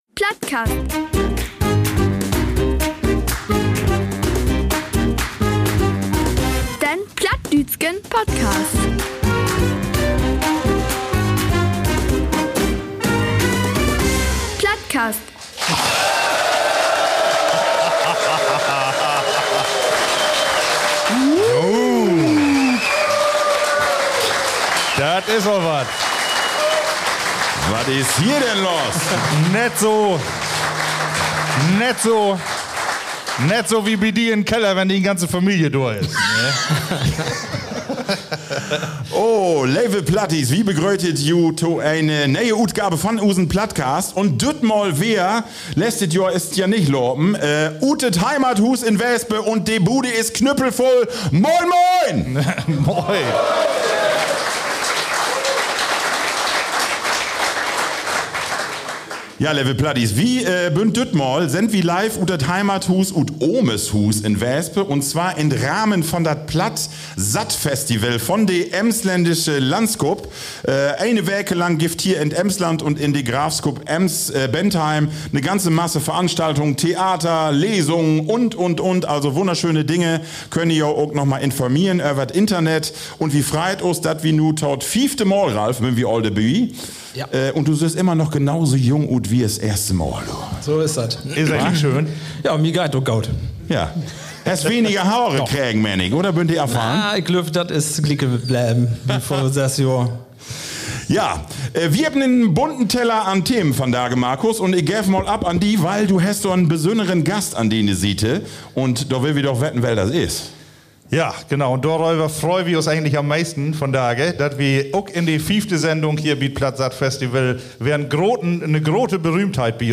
Im Rahmen des PlattSatt Festivals 2025 haben wir im heimeligen Ambiente des Heimathauses Wesuwe vor 150 bestens gelaunten Gästen eine Live-Sendung aufgenommen – mit keinem Geringeren als dem Mitglied des Deutschen Bundestages und Staatssekretär, Johann Saathoff. Der gebürtige Ostfriese aus Emden plauderte humorvoll, pointiert und natürlich auf Platt über sein Leben, seinen Weg in die Politik und seine große Leidenschaft: den Erhalt der plattdeutschen Sprache.